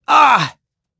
Siegfried-Vox_Damage_02.wav